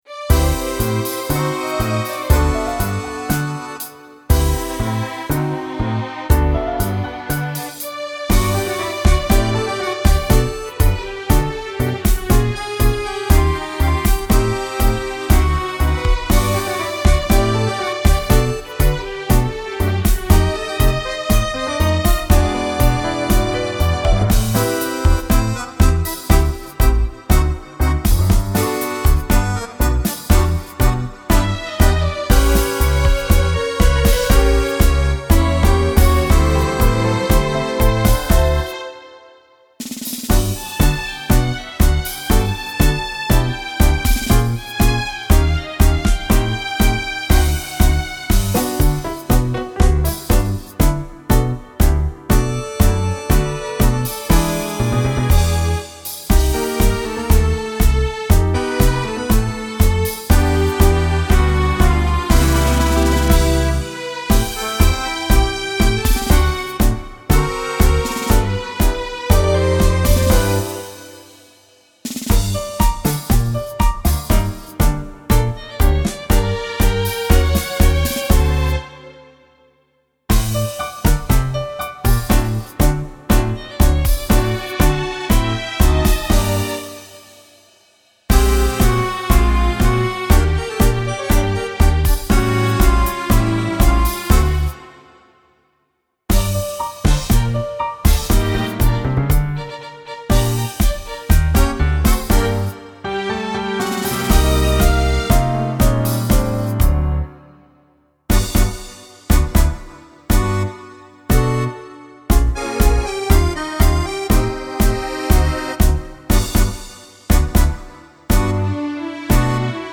(solo base)